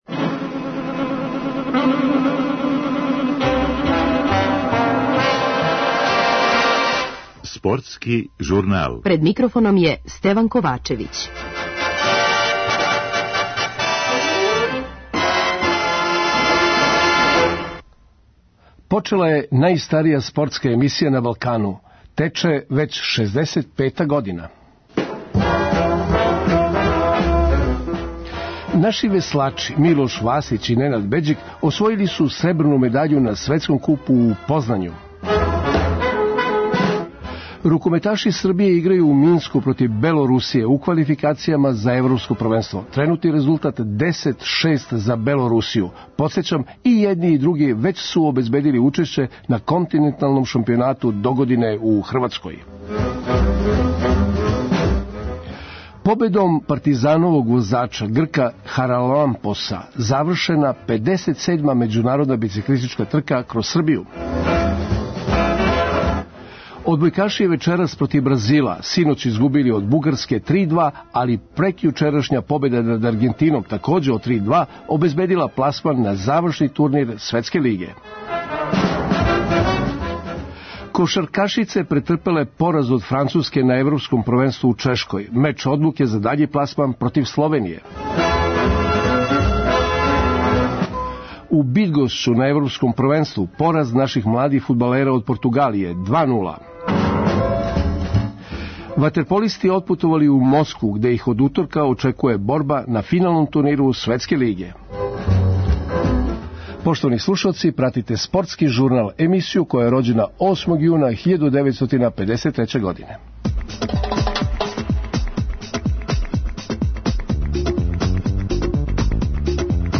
Укључићемо репортера РТС-а са трасе последње етапе 57. међународнма бициклистичке трке „Кроз Србију".